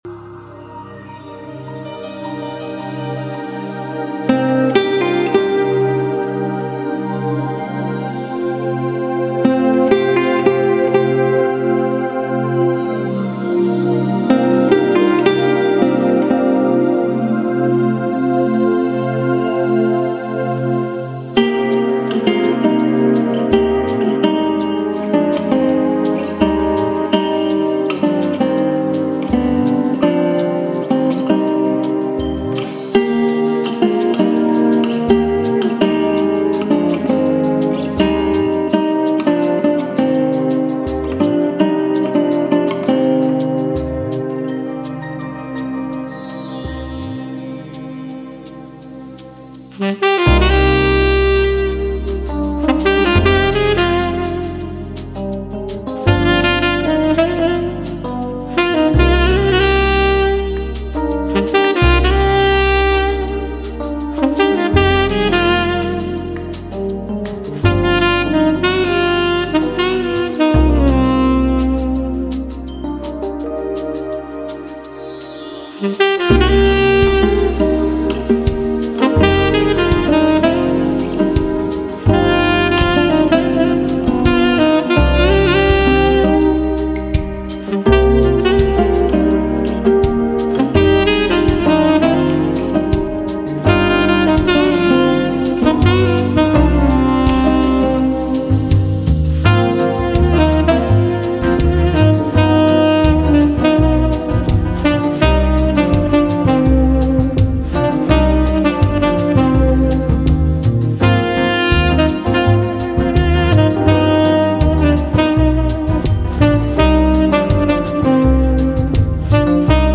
薩克斯風更增添其催淚指數